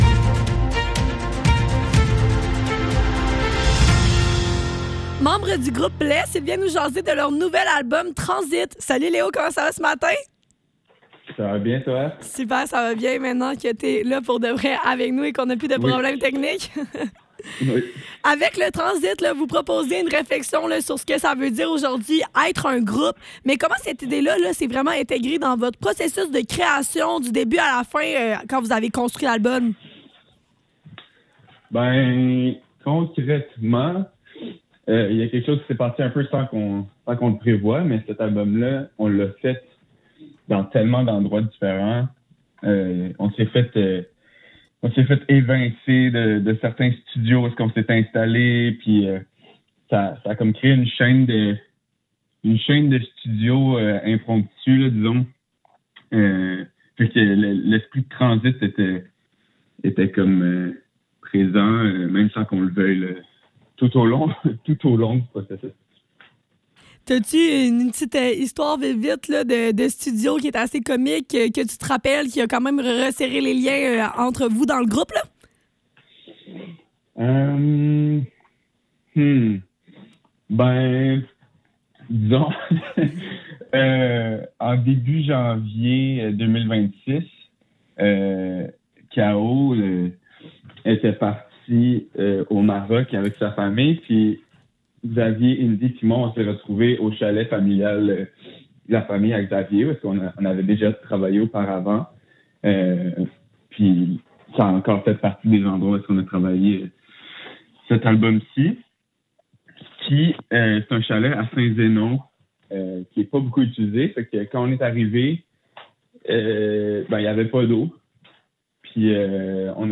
Le Neuf - Entrevue avec blesse dans le cadre de leur nouvel album: transit - 9 avril 2026